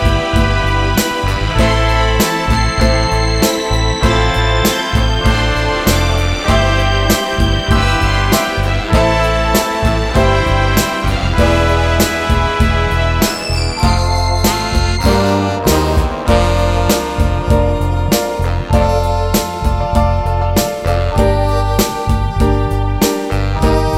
no Backing Vocals Soul / Motown 3:10 Buy £1.50